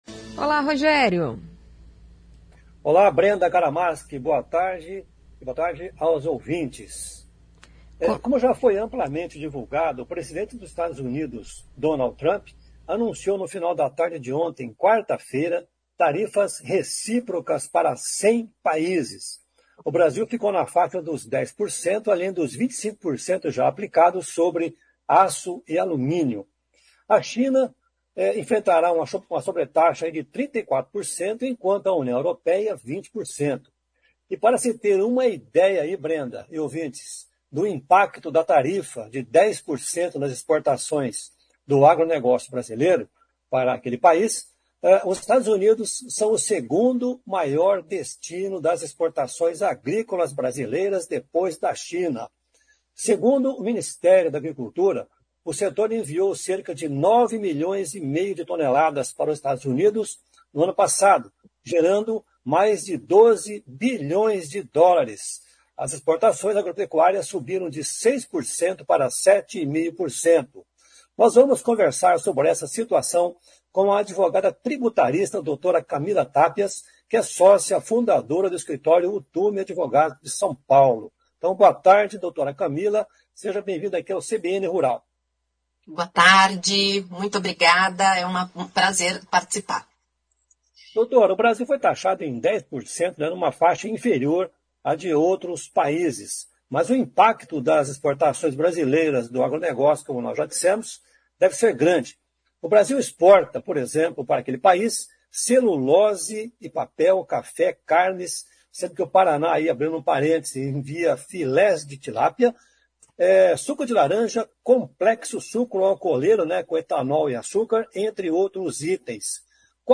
conversa com a advogada tributarista